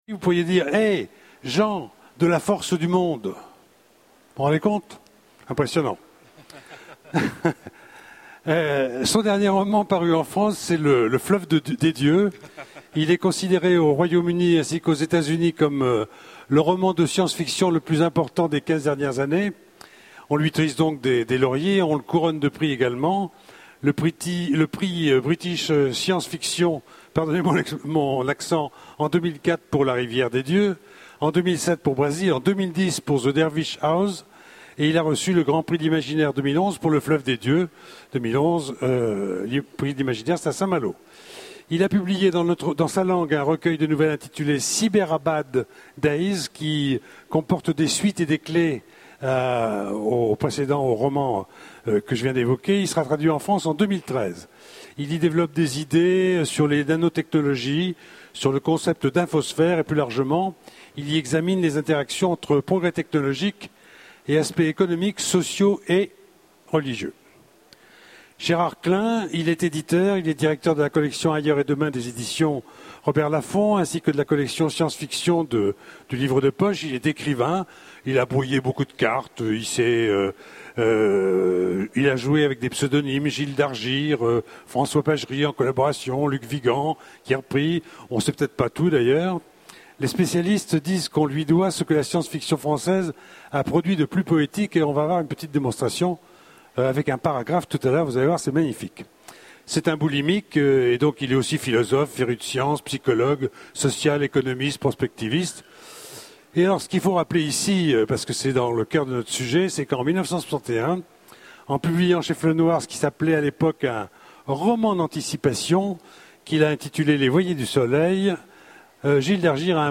Utopiales 2011 : Conférence La SF est-elle un bon vecteur de vulgarisation ?